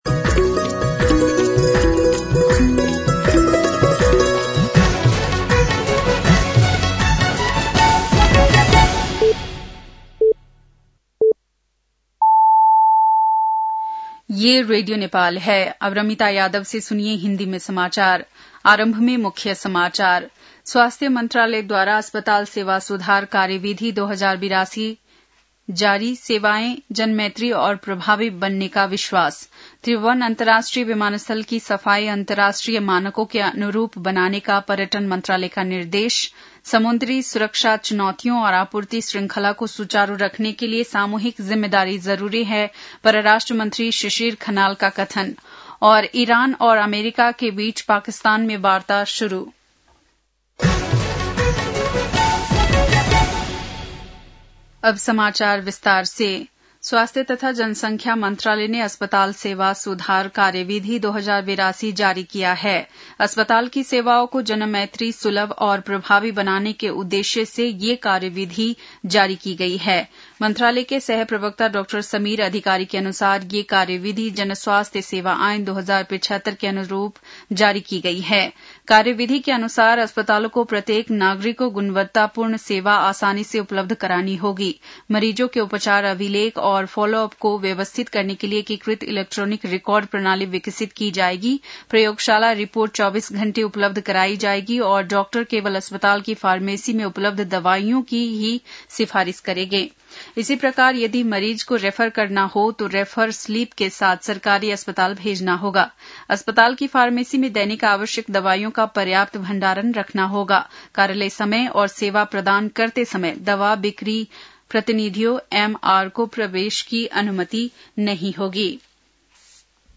बेलुकी १० बजेको हिन्दी समाचार : २८ चैत , २०८२